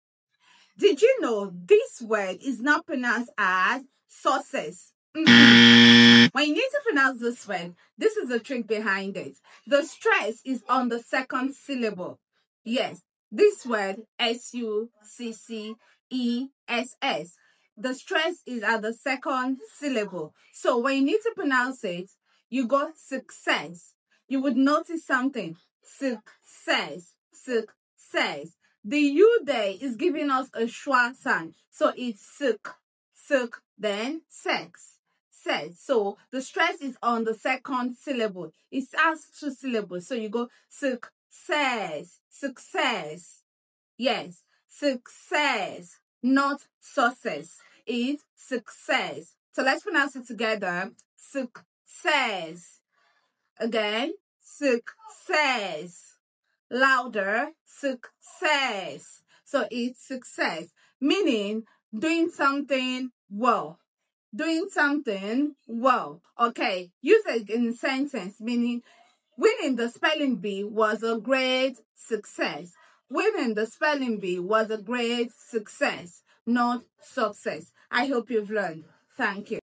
🔎 Pronunciation: suhk – SESS (stress on the second part) 📖 Meaning: Doing something well